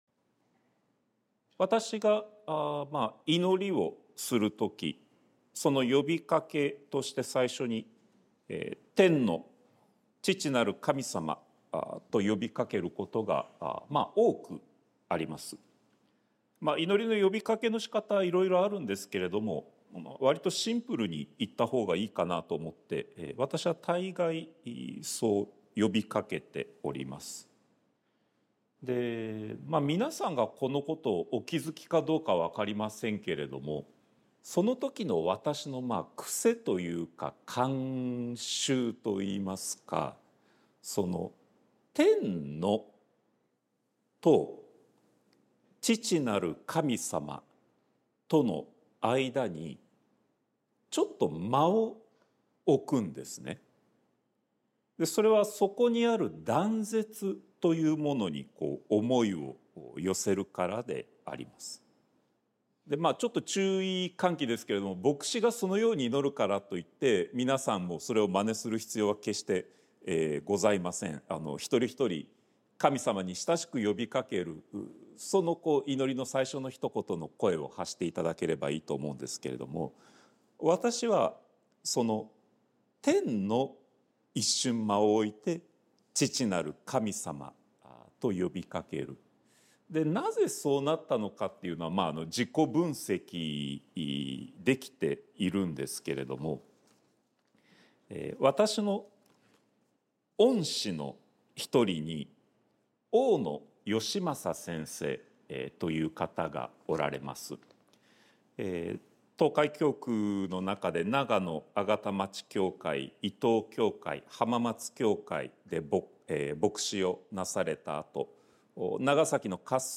sermon-2024-08-11